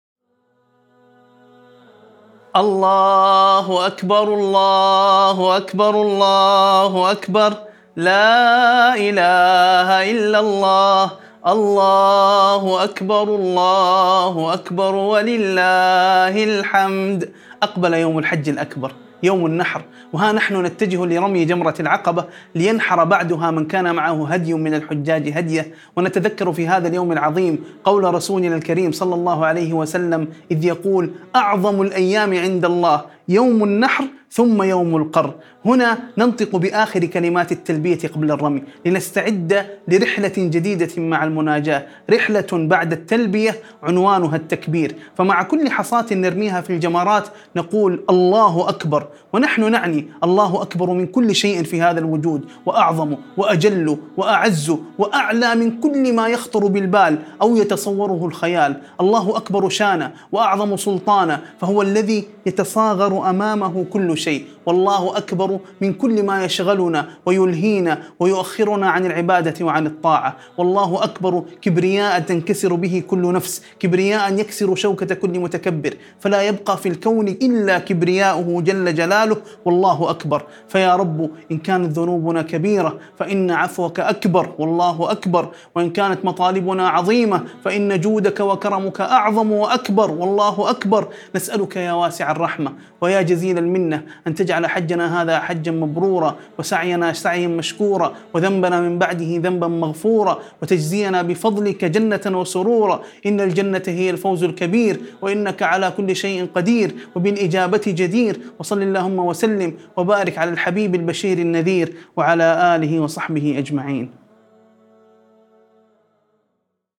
مقطع صوتي مؤثر يتناول مناسك الحج في يوم النحر، مع التركيز على معاني التكبير والتلبية ورمي الجمرات. يحتوي على دعاء جامع للحجاج يطلب قبول الحج ومغفرة الذنوب والفوز بالجنة، مستشهداً بحديث النبي صلى الله عليه وسلم عن فضل يوم النحر.